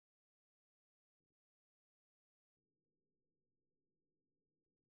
I took a clean 1kHz sine wave—the digital equivalent of a blank sheet of paper—and I ran it through a forensic corruption script.
I simulated thermal noise, bit-flipping, and the slow, grinding decay of a dying channel.
Listen to the jitter.
That’s the sound of a system losing its grip on the signal.